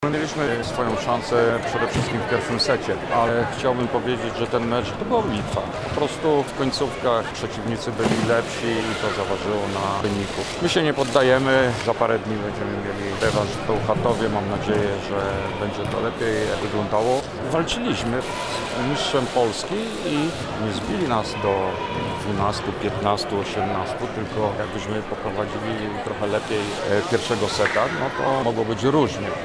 – mówił po spotkaniu trener PGE GiEK Skry Bełchatów, Krzysztof Stelmach.